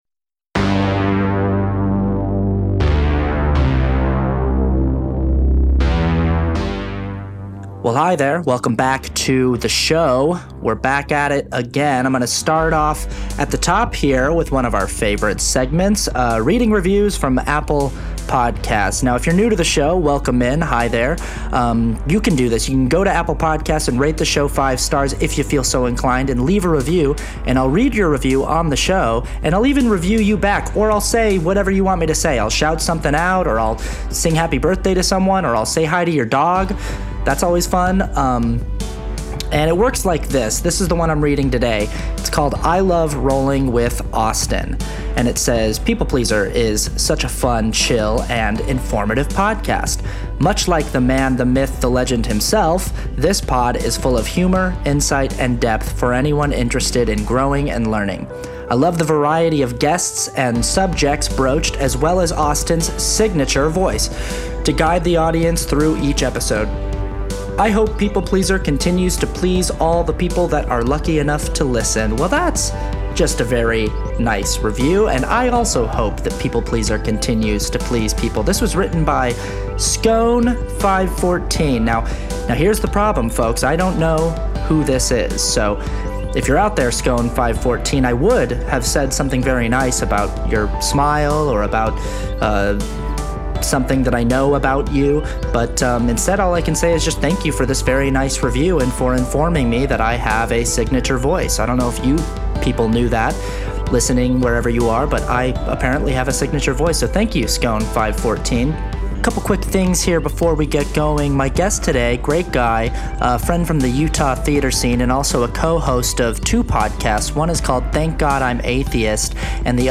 A candid anecdotal chat on Depression and Anxiety from two non-experts.